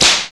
CRACK.wav